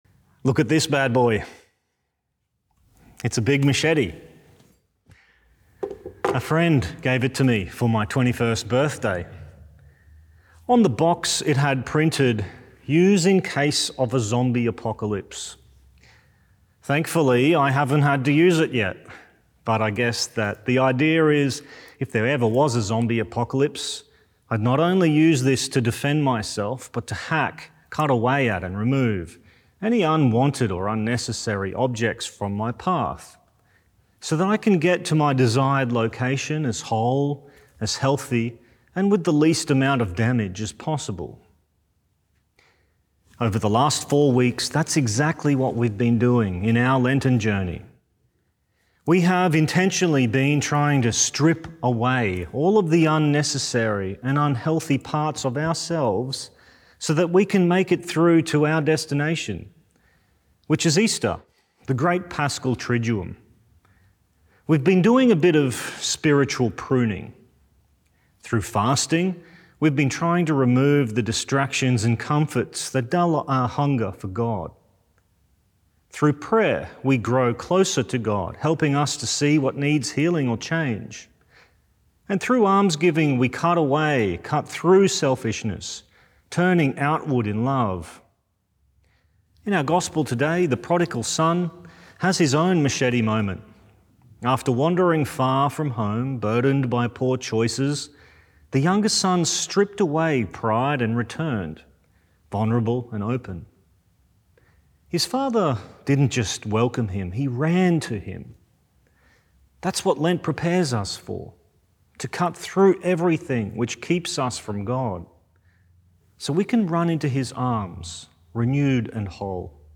Archdiocese of Brisbane Fourth Sunday of Lent - Two-Minute Homily